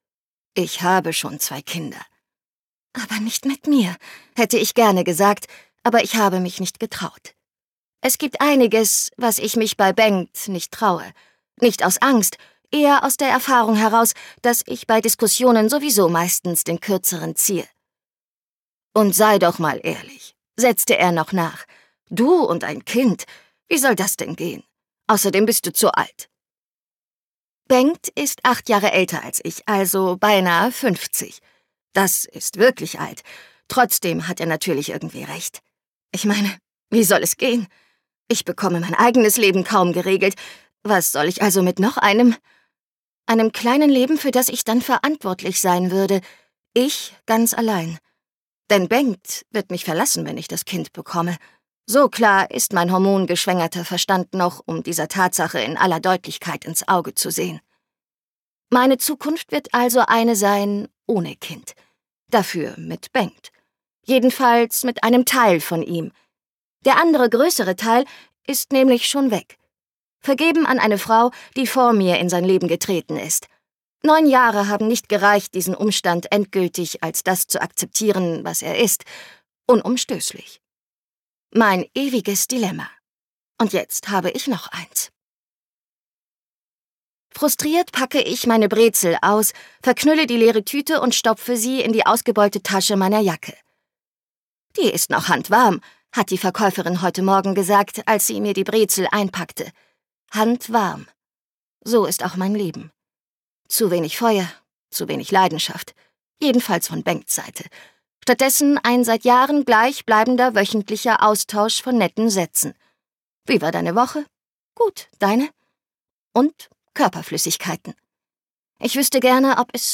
Wir für uns - Barbara Kunrath | argon hörbuch
Gekürzt Autorisierte, d.h. von Autor:innen und / oder Verlagen freigegebene, bearbeitete Fassung.